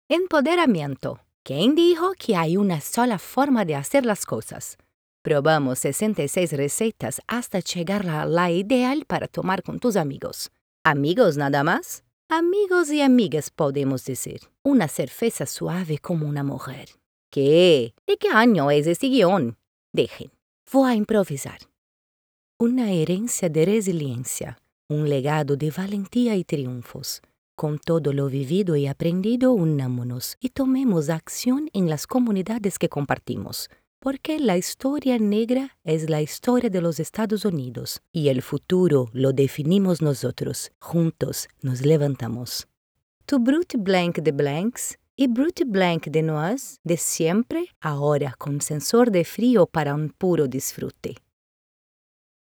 Comercial
With a versatile, mature, and pleasant voice, I am able to adapt my narration style to the needs of the project, creating a unique and engaging experience for the listener.
With an excellent acoustic treatment system that offers excellent quality.